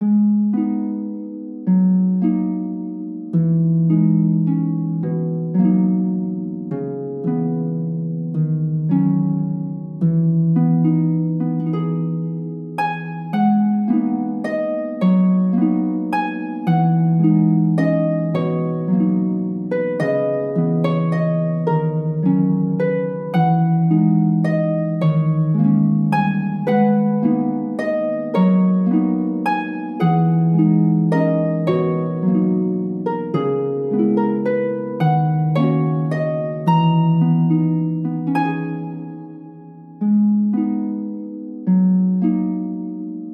お得意の切ない系短めハープの曲です